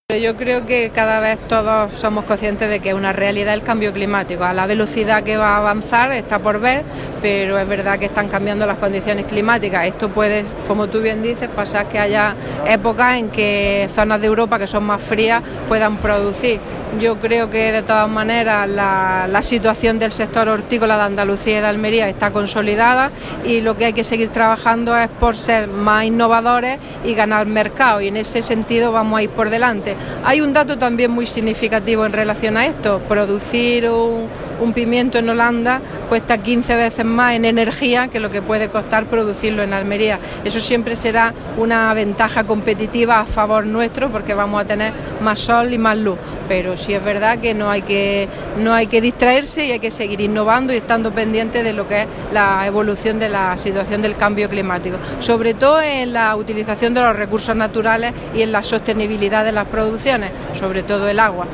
Declaraciones de Carmen Ortiz sobre precios de productos hortofrutícolas